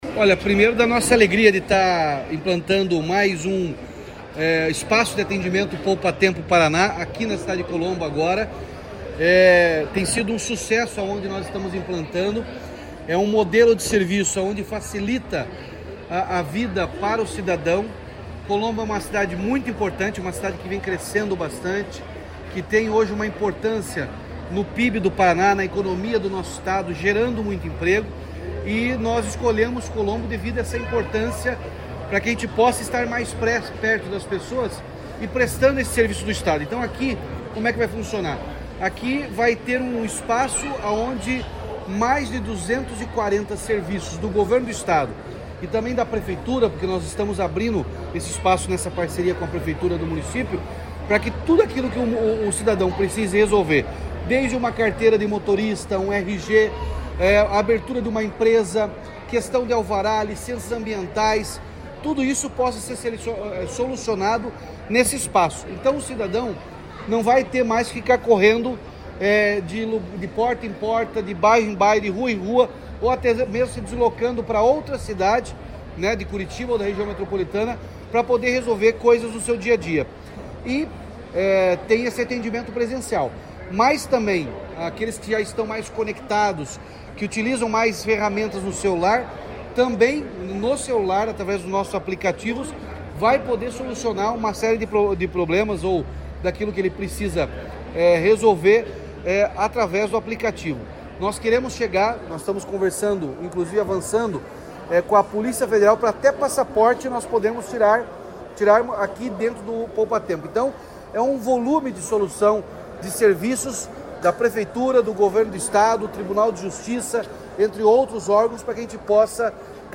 Sonora do governador Ratinho Junior sobre a nova unidade do Poupatempo Paraná em Colombo